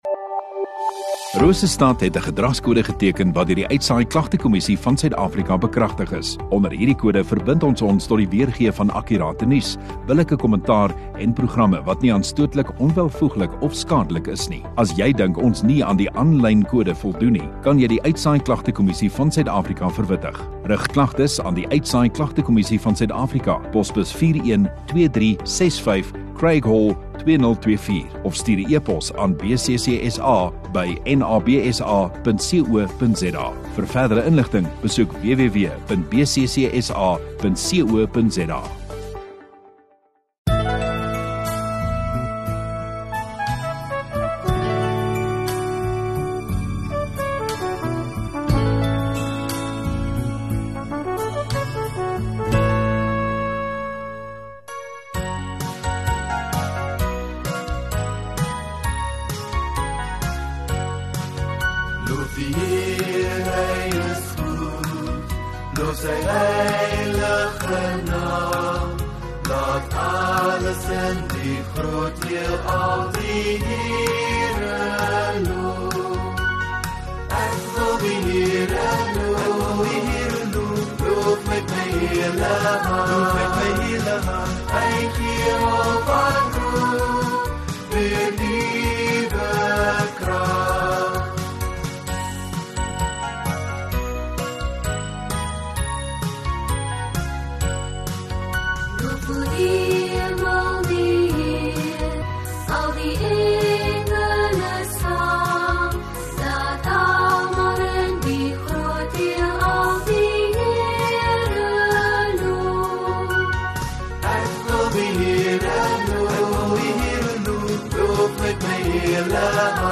21 Sep Sondagaand Erediens